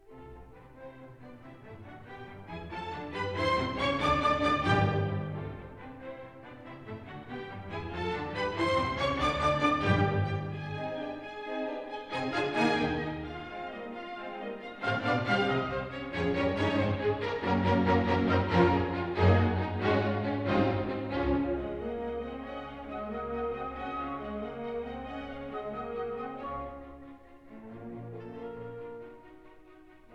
in C major, Op. 21
in the Kingsway Hall, London